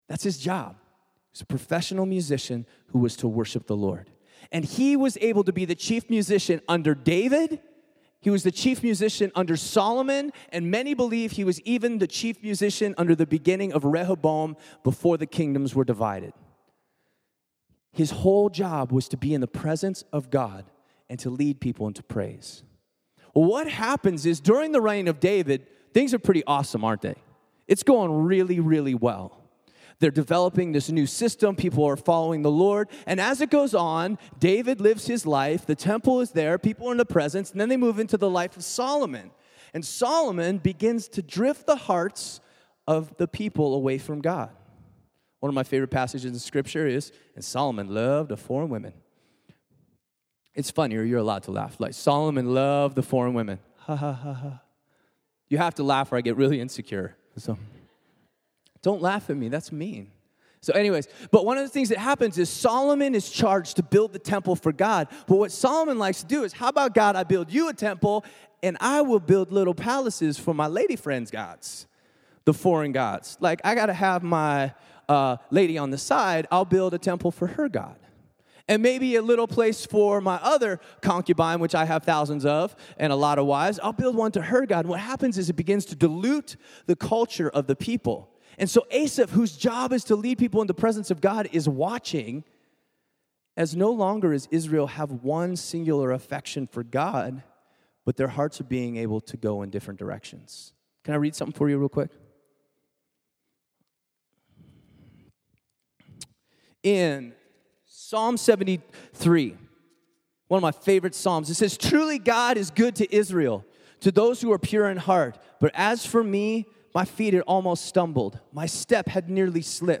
Sermons - The Well